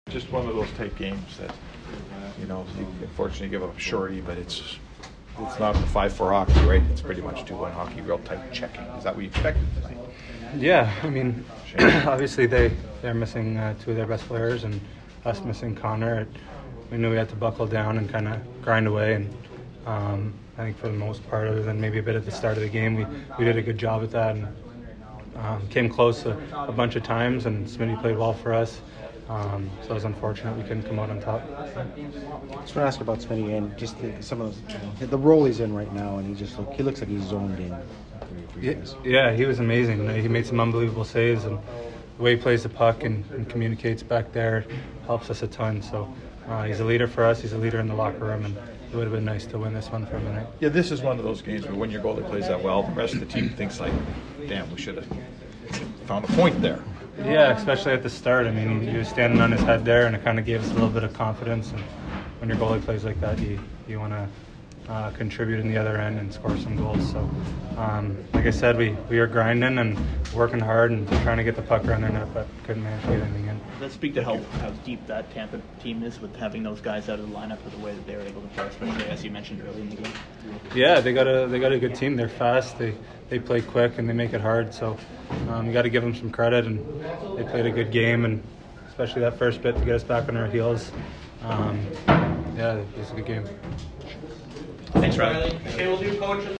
Riley Sheahan post-game 2/13